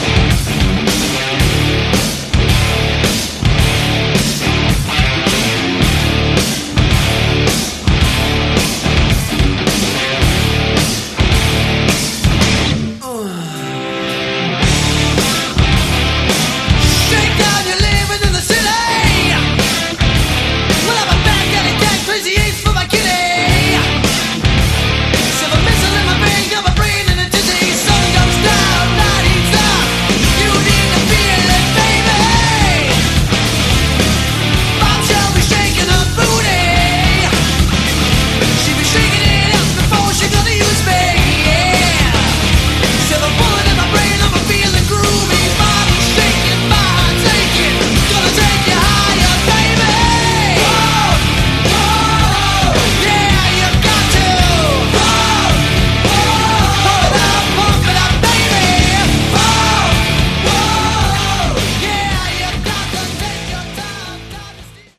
Category: Sleaze - Glam